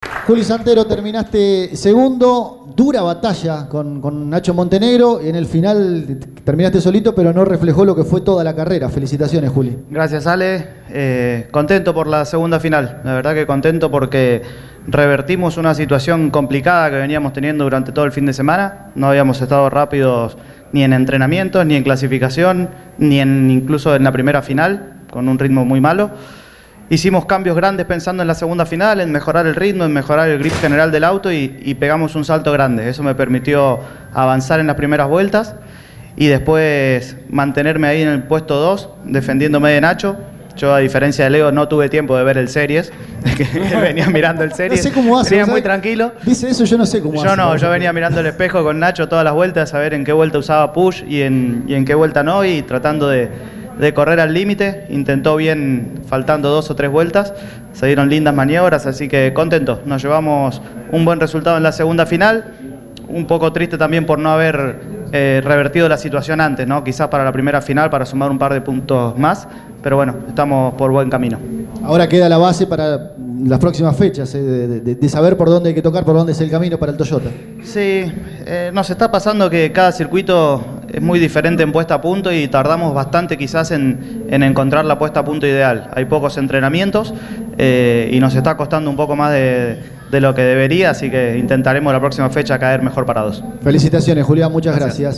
El mendocino pasó por los micrófonos de Pole Position y manifestó su alegría por el podio obtenido en la segunda carrera, revirtiendo un fin de semana que venía siendo cuesta arriba con el funcionamiento del Toyota Corolla.